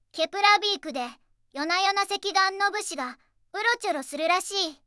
voicevox-voice-corpus
voicevox-voice-corpus / ROHAN-corpus /ずんだもん_ツンツン /ROHAN4600_0035.wav